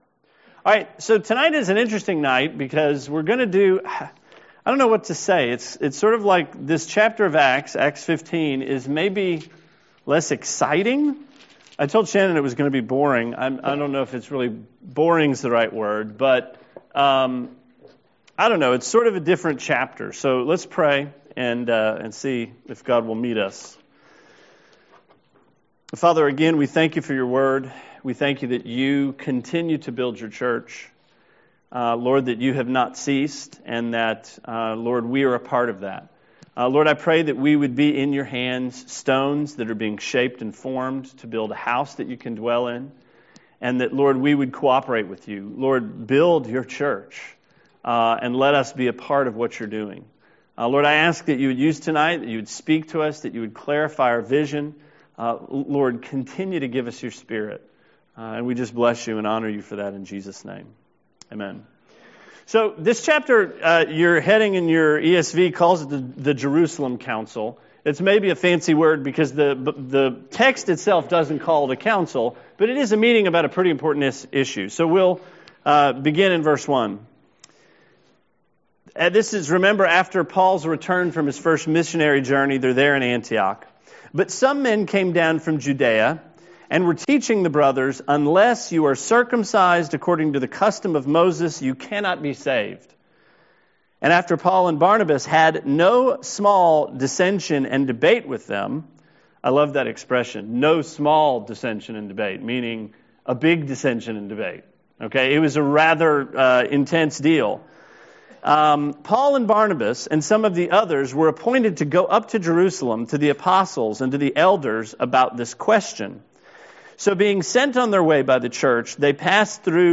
Sermon 6/17: Acts 15